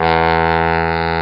Bari Low Sound Effect
bari-low.mp3